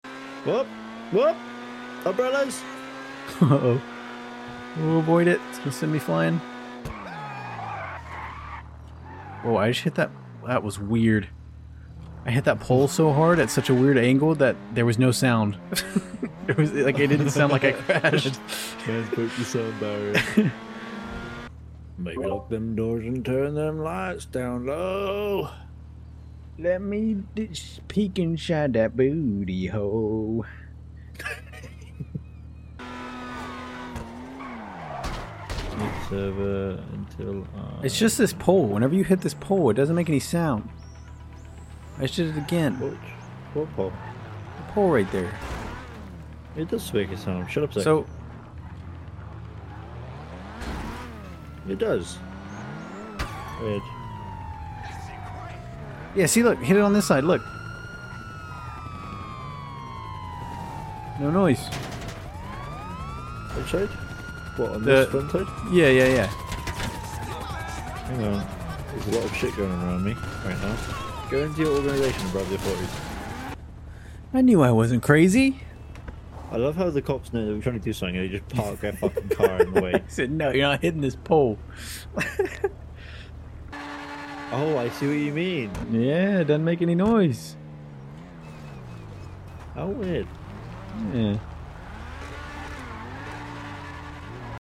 I found an Audio Glitch sound effects free download